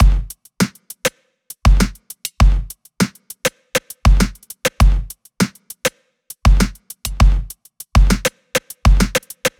Index of /99Sounds Music Loops/Drum Loops/Dance